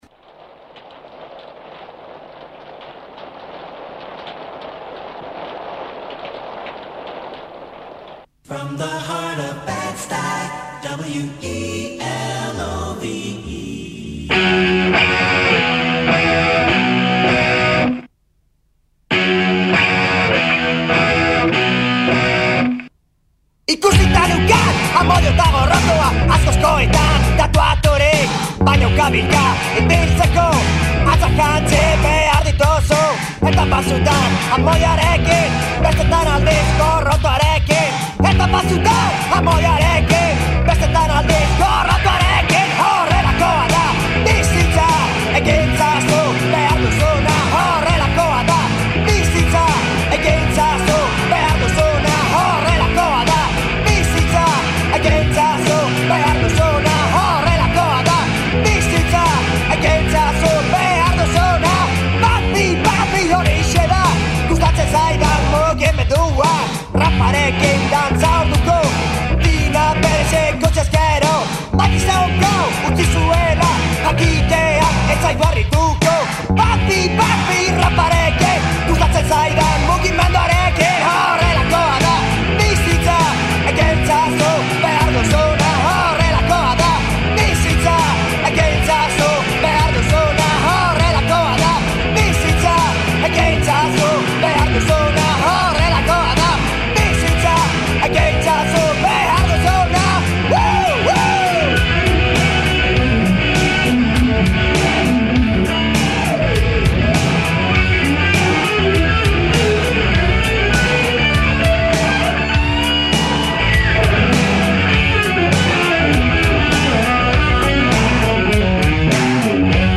Un viaggio musicale dentro le culture latino americane.